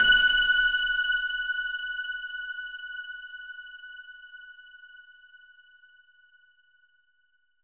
SOUND  F#5.wav